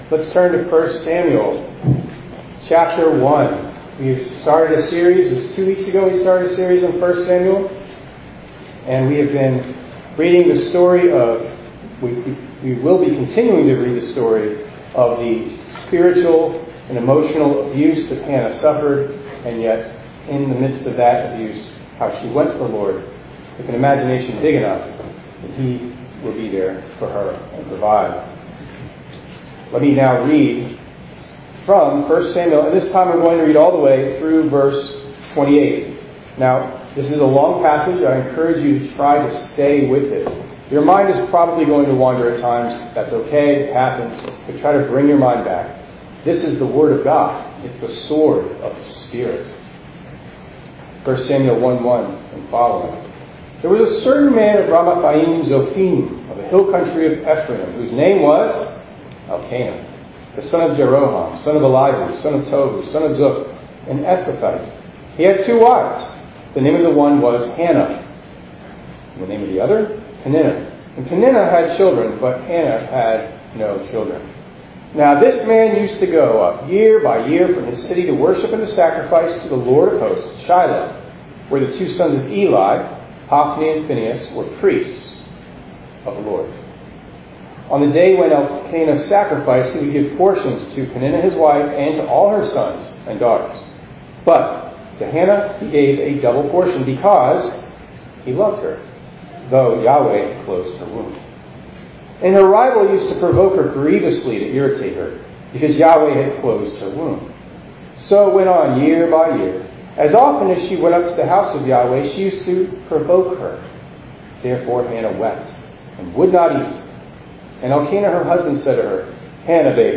6_23_24_ENG_Sermon.mp3